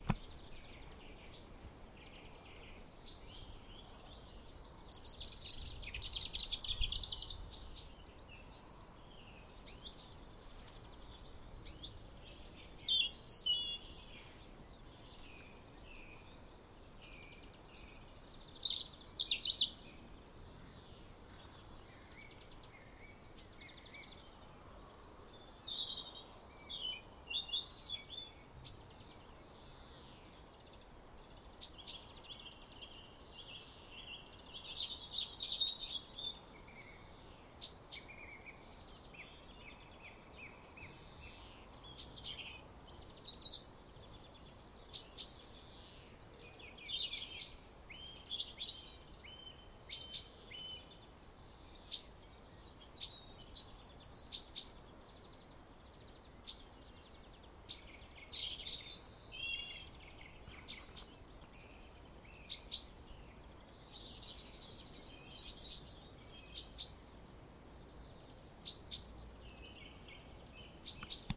当我在珀斯郡/苏格兰4月的一个早晨，大约5点20分开始这个录音时，你还能听到一只黄褐色的猫头鹰。两个AT 3031话筒，一个来自舒尔的FP24前级放大器，所有这些都输入到R09HR录音机中。
Tag: 鸟鸣 黑鸟 dawnchorus 现场记录 循环赛 苏格兰 tawnyowl